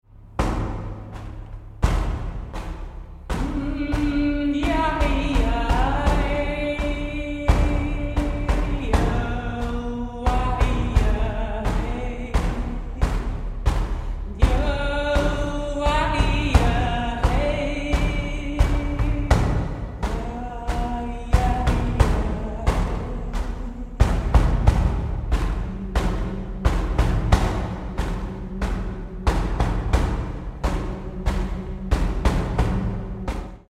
Elle rend audible cette vibration par les sons qu’elle chante,
accompagnée de ses instruments.